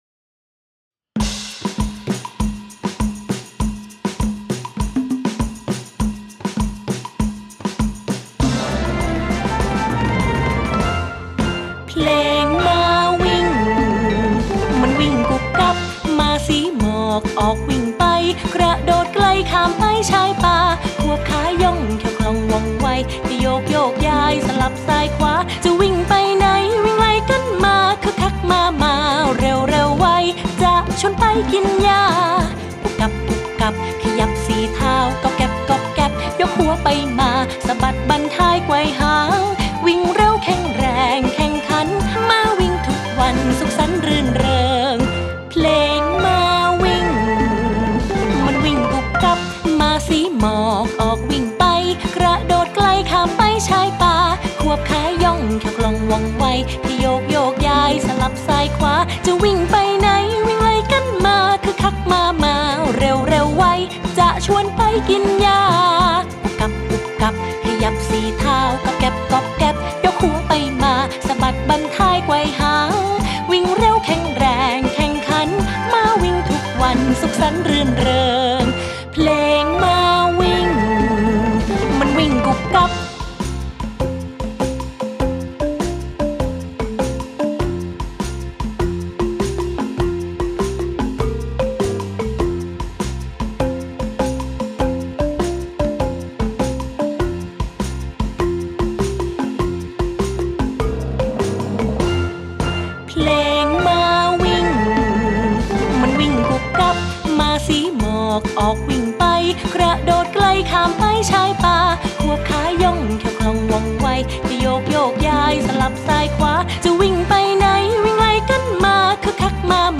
ไฟล์เพลงพร้อมเสียงร้อง